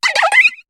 Cri de Carapagos dans Pokémon HOME.